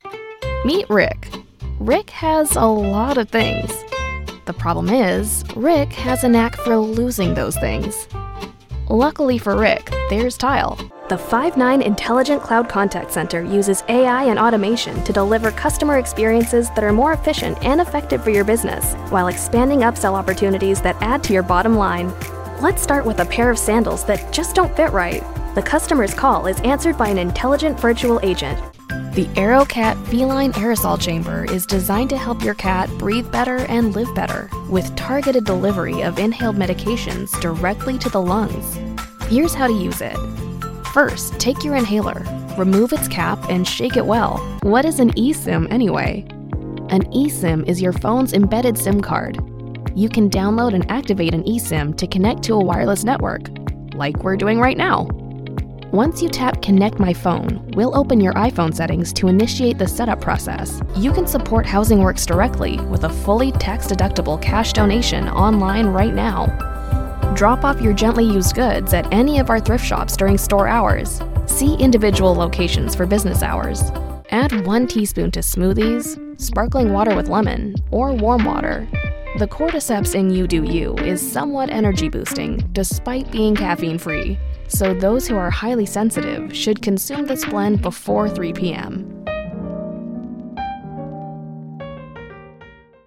Comercial, Natural, Travieso, Versátil, Amable
Explicador